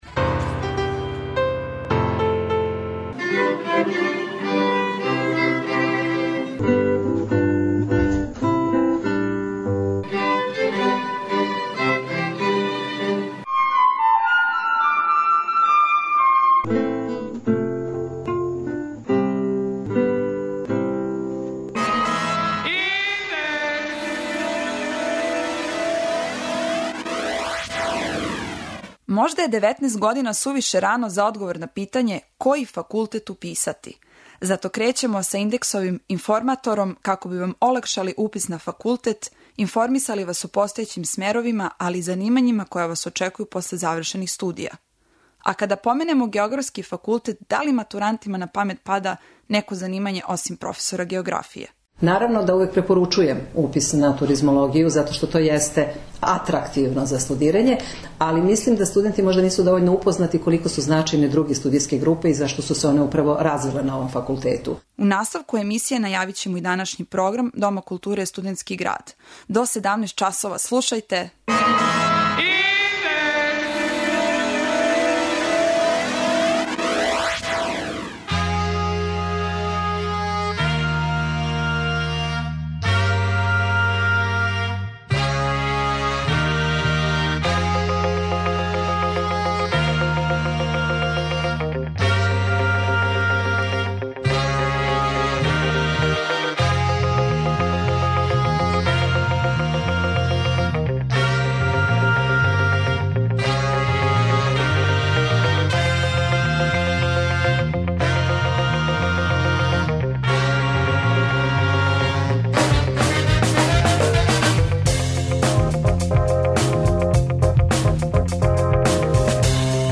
Први факултет са којим ћемо се упознати је Географски факултет у Београду. Чућете мишљење професора, али и искуства садашњих студената.
преузми : 20.27 MB Индекс Autor: Београд 202 ''Индекс'' је динамична студентска емисија коју реализују најмлађи новинари Двестадвојке.